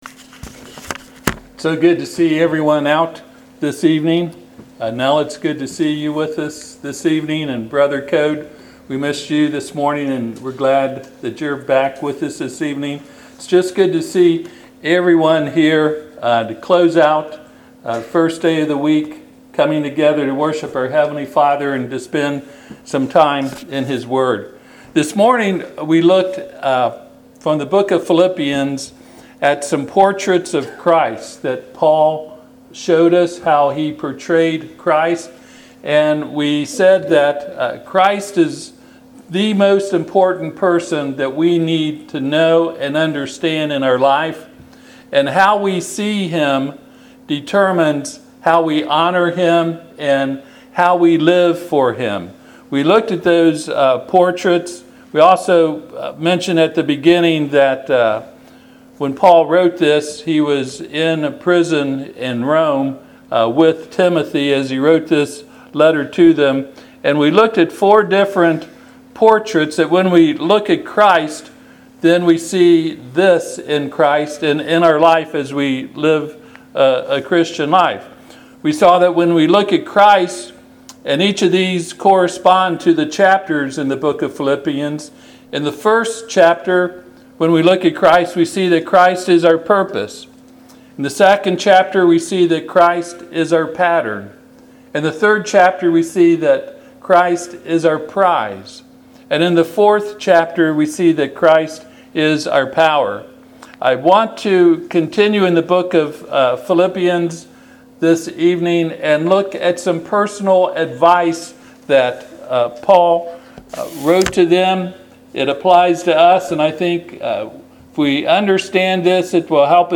Passage: Philippians 3:13-14 Service Type: Sunday PM